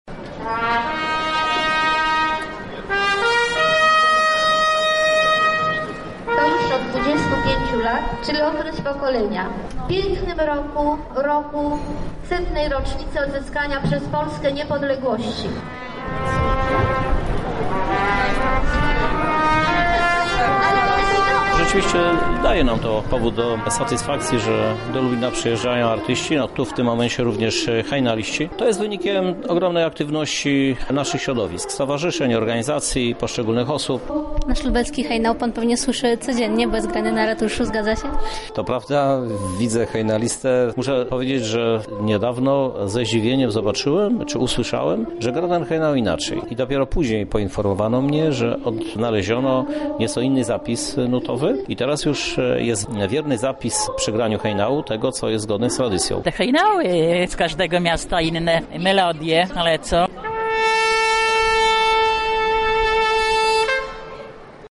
W obchodach wziął udział między innymi Prezydent Lublina, Krzysztof Żuk: